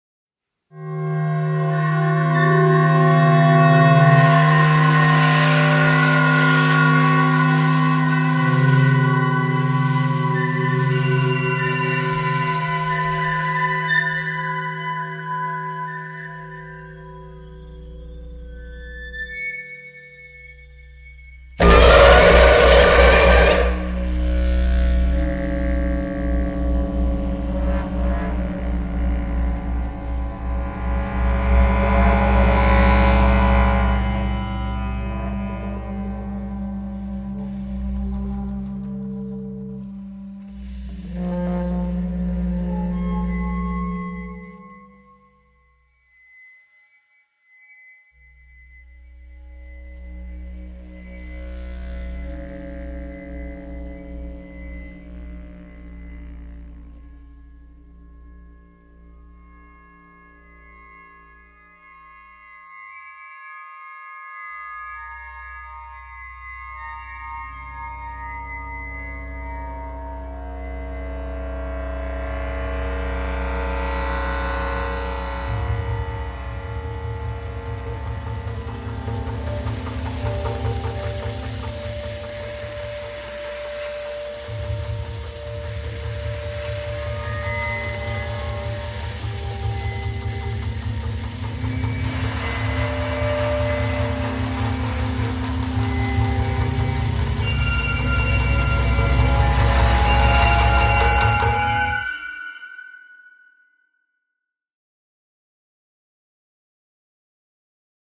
Un mot m’est venu à l’écoute : lugubre !